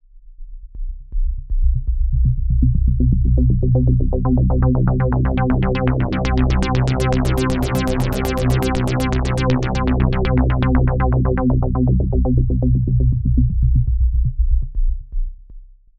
Subtraktive Synthese
Hier wird der Grundsound durch eine einfache Oszillatorwelle erzeugt. Gleich im Anschluss folgt die Absenkung von Bässen, Mitten oder Höhen bzw. von einer Kombination aus mehreren Frequenzbereichen.
syntheseformen_klangsynthese_faq__subtraktive_synthese.mp3